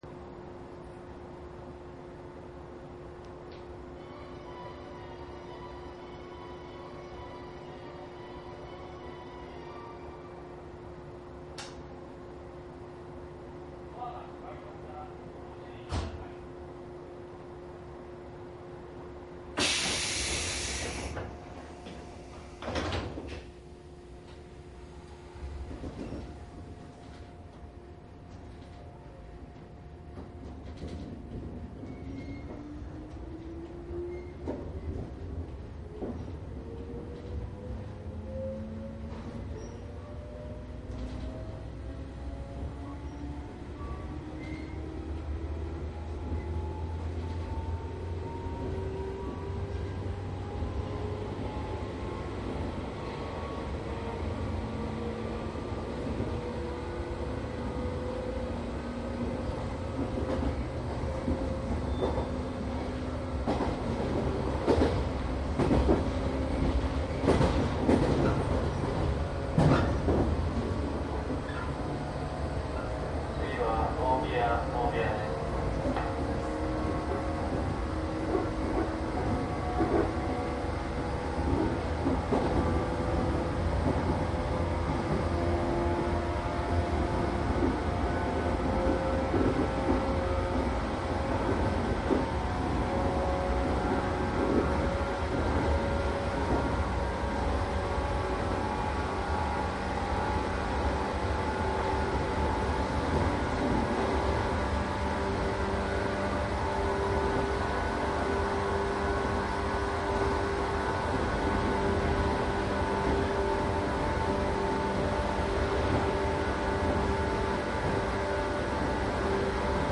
♫JR高崎線【上り】115系電車　走行音ＣＤ♪
マスター音源はデジタル44.1kHz16ビット（マイクＥＣＭ959Ａ）で、これを編集ソフトでＣＤに焼いたものです。
貸切ではないので乗客の会話などが全くないわけではありません。